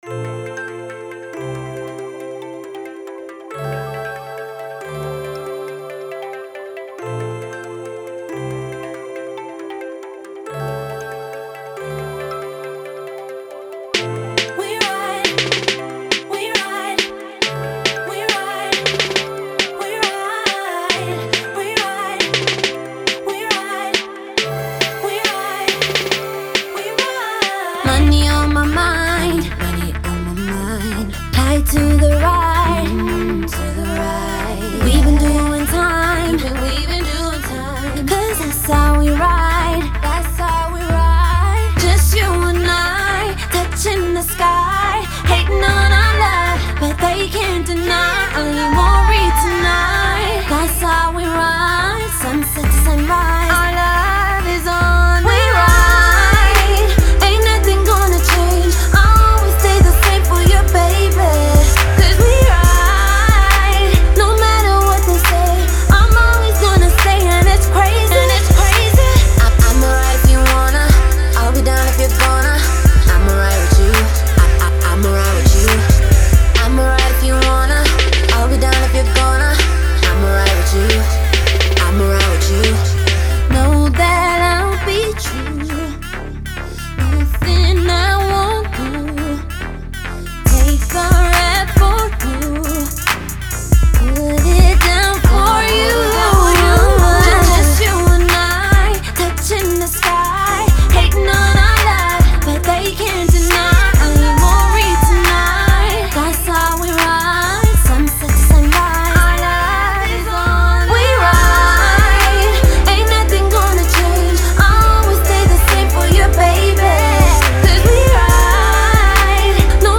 South African songstress
Cool jam for that slow /chill- mode playlist.